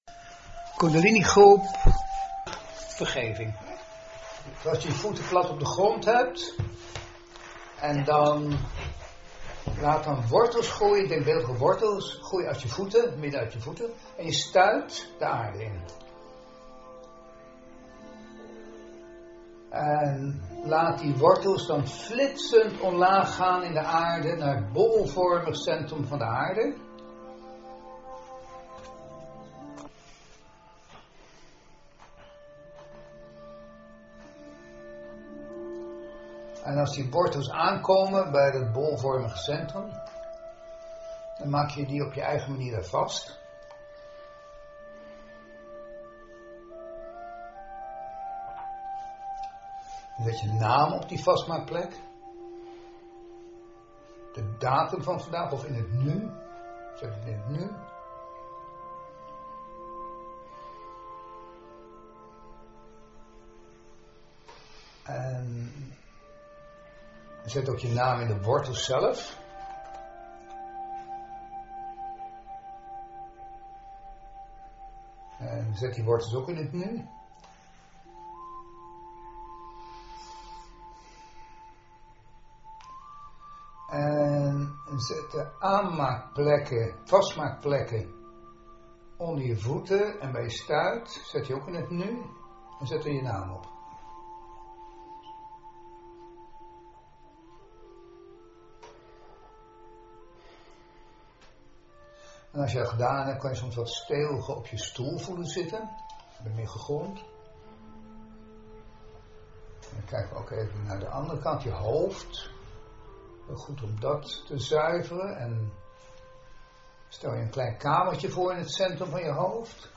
Hieronder staat als hulpmiddel een meditatie waarbij je stapsgewijs kunt proberen te vergeven.